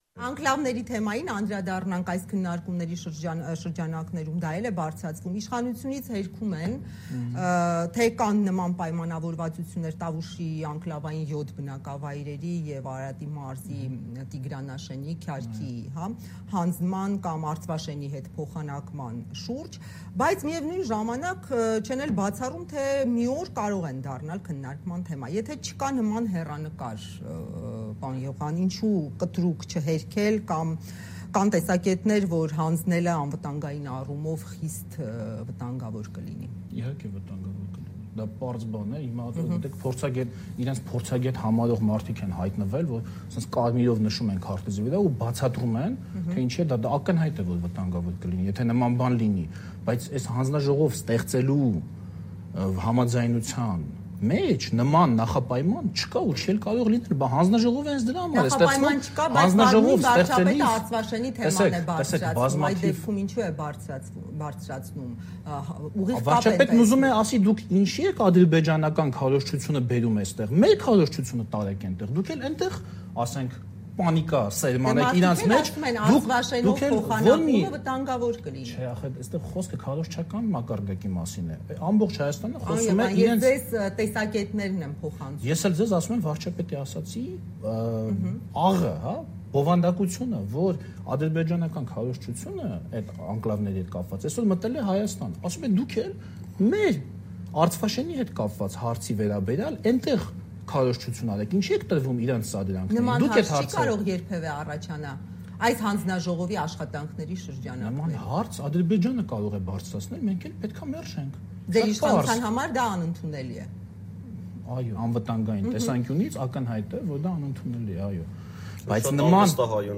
Ինչ փաստաթուղթ է պատրաստվում ստորագրել Փաշինյանը. բանավեճ